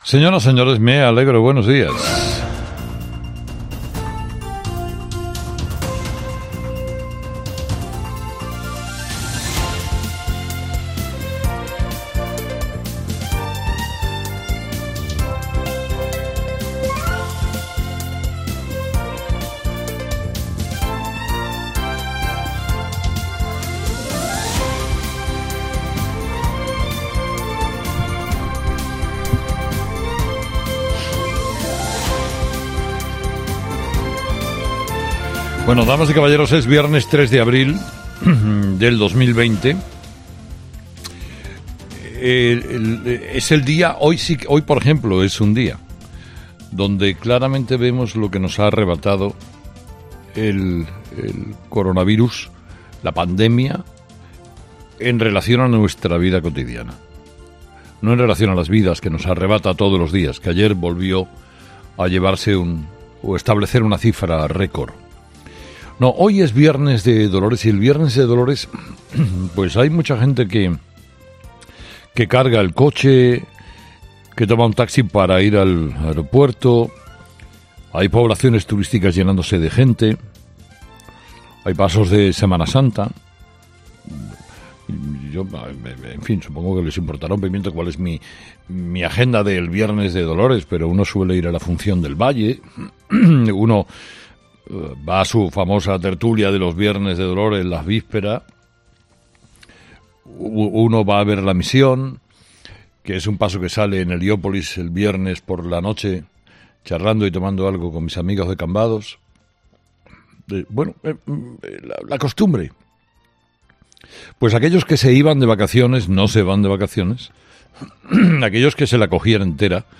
ESCUCHA EL EDITORIAL DE CARLOS HERRERA